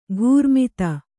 ♪ ghūrmita